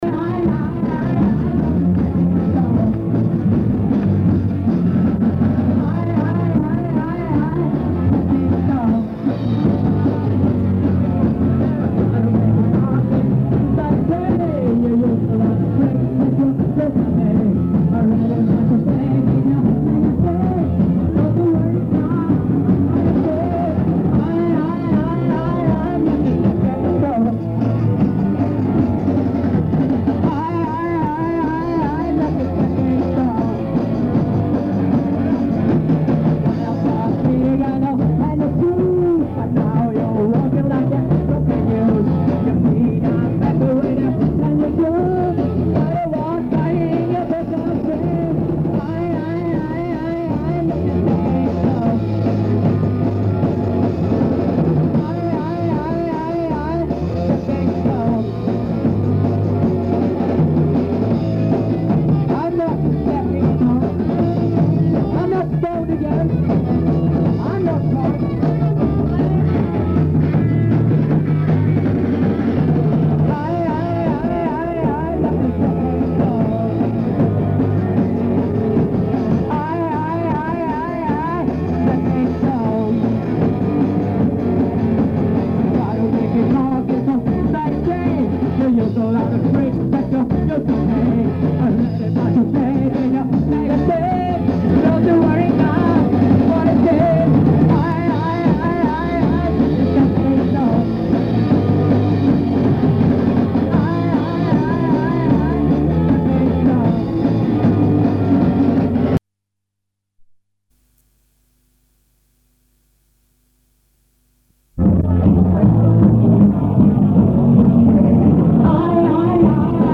punkband